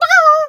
chicken_2_bwak_03.wav